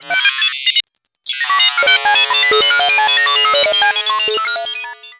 S_H Hold Electron.wav